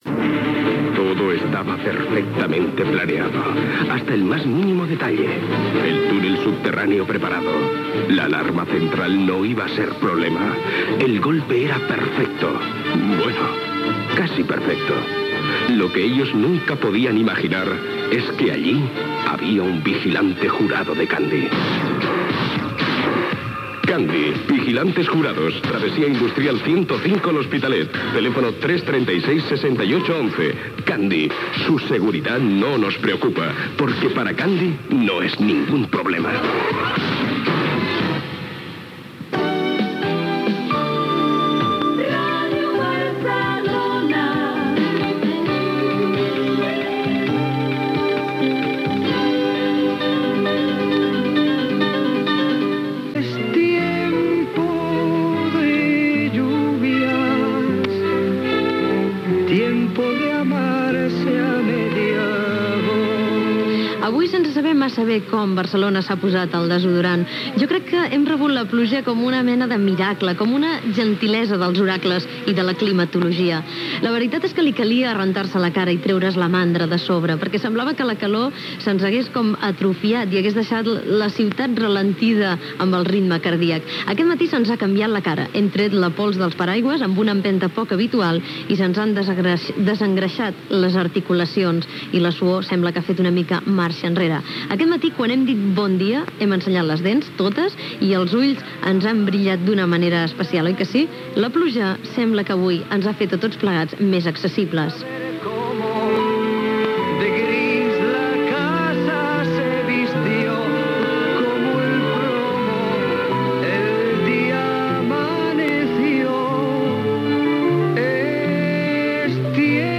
Publicitat (veu Jordi Hurtado), indicatiu de l'emissora, indicatiu del programa, cançó, comentari sobre el temps plujós, hora, enquesta sobre la pluja, concurs del programa, indicatiu del programa
Entreteniment